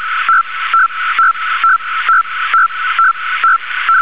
Digital Modes: Audio Files Download these files to your laptop to play for students to introduce them to the many digital modes radio amateurs employ (all files presented below are in wav format except as noted).
AMTOR ARQ.wav